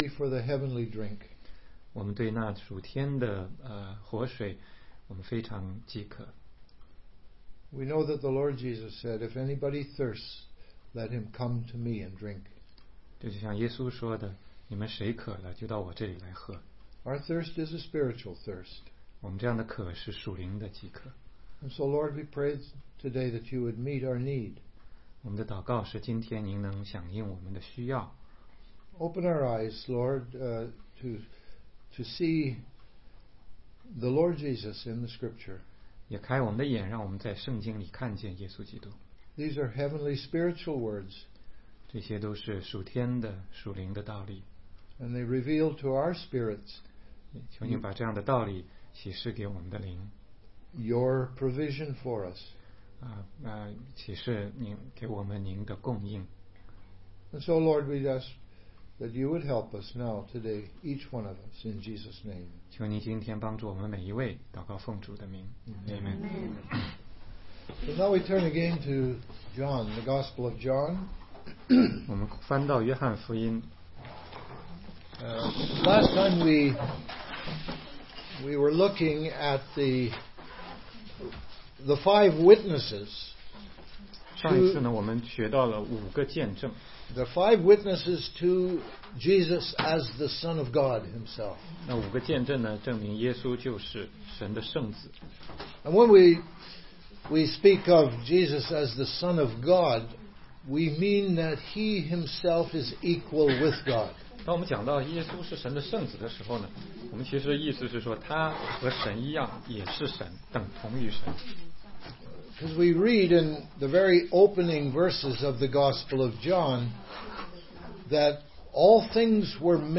16街讲道录音 - 约翰福音5章41-47节